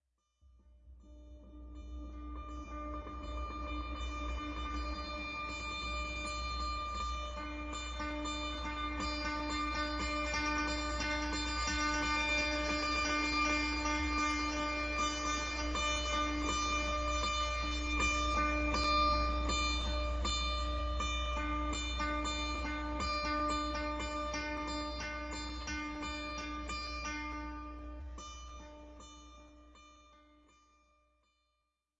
凯尔特人伊斯兰教 808 Dub Beat
描述：808配音节拍
Tag: 155 bpm Dub Loops Drum Loops 1.04 MB wav Key : Unknown